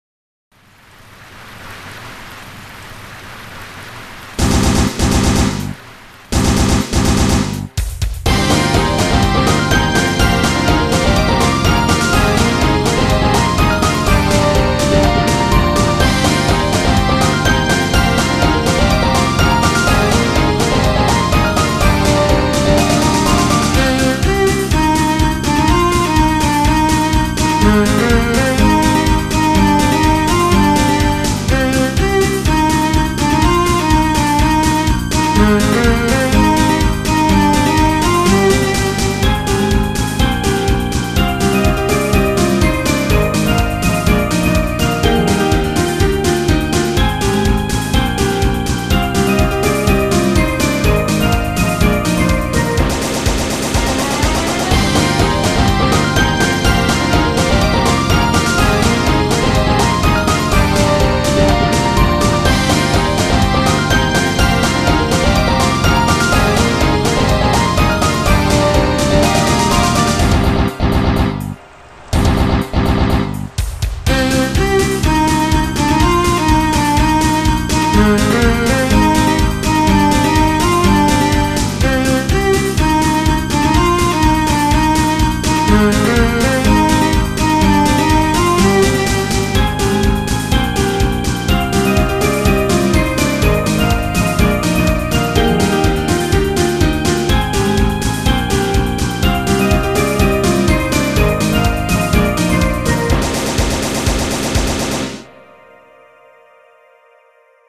中ボスっぽいヘヴィな中に胡散臭さが溢れています。